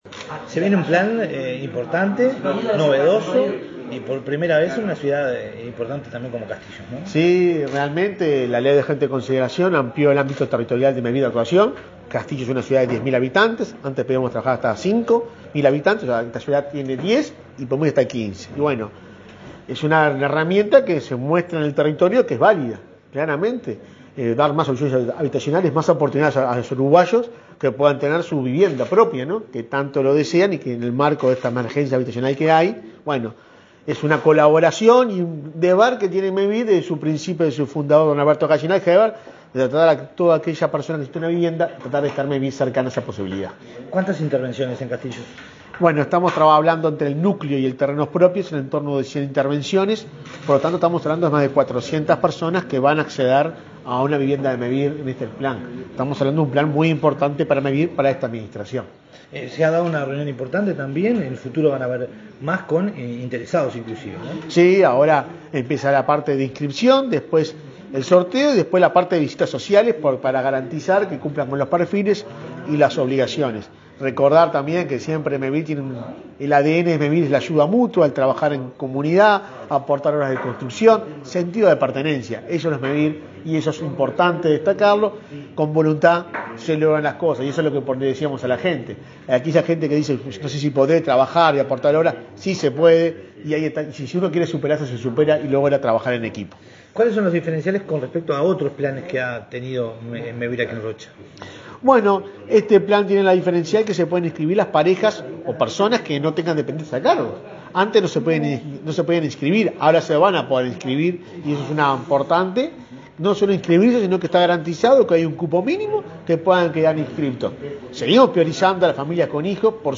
Declaraciones a la prensa del presidente de Mevir, Juan Pablo Delgado